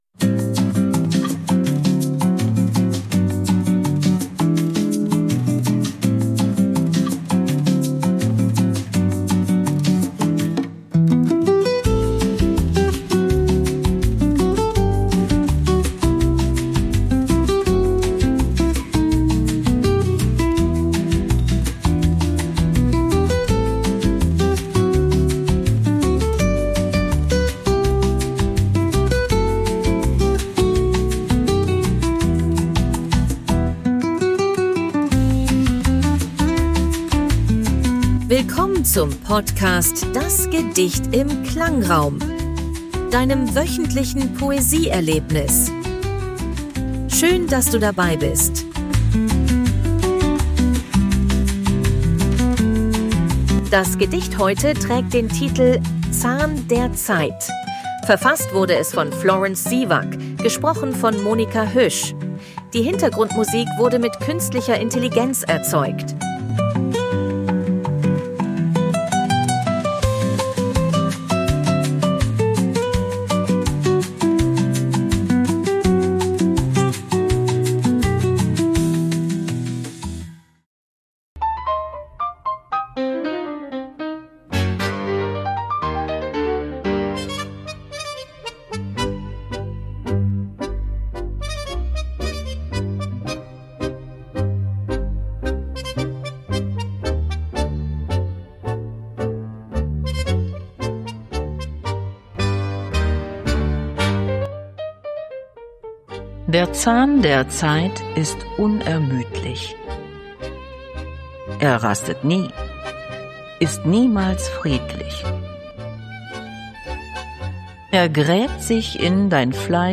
Die Hintergrundmusik wurde mit KI